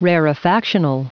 Prononciation du mot rarefactional en anglais (fichier audio)